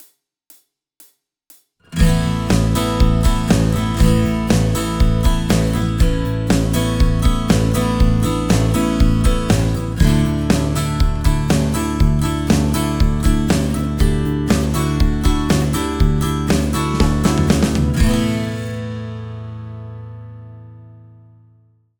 ギター：Gibson J45
マイクプリ：SSL XLogic（EQやコンプは不使用）
録音後には一切処理を行わず、そのまま書き出しています。
4/4拍子 ストレートな開放コードのレコーディング
LCT 240 PRO バンドミックスサンプル（ジョイント部分を狙ったもの）